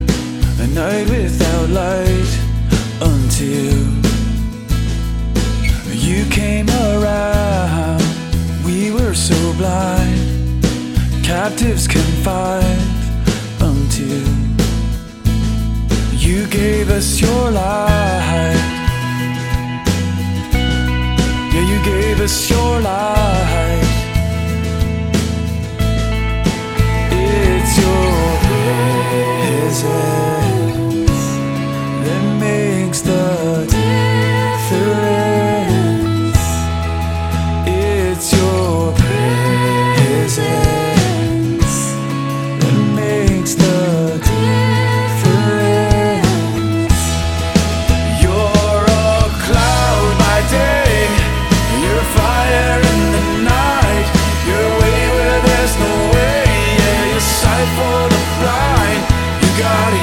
zeitgemäße, gemeindetaugliche Lobpreismusik
• Sachgebiet: Praise & Worship